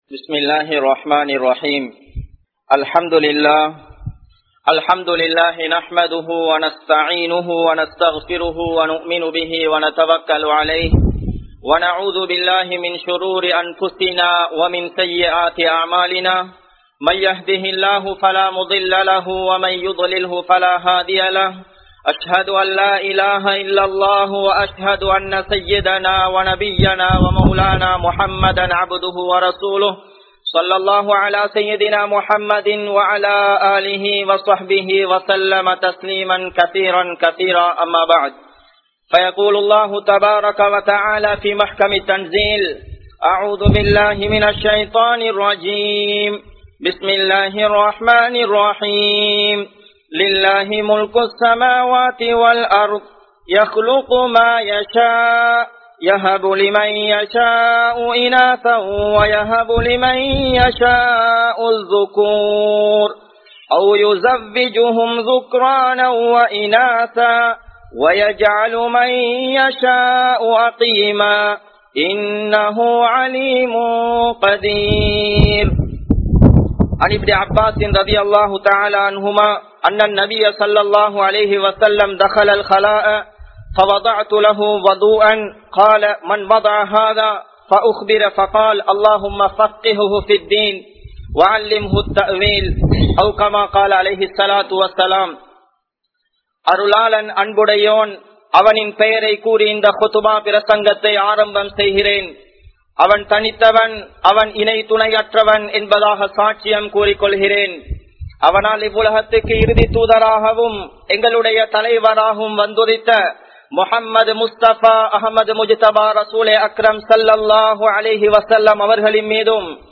Pillaihalukkaaha DUA Seiungal (பிள்ளைகளுக்காக துஆ செய்யுங்கள்) | Audio Bayans | All Ceylon Muslim Youth Community | Addalaichenai
Galle, Kanampittya Masjithun Noor Jumua Masjith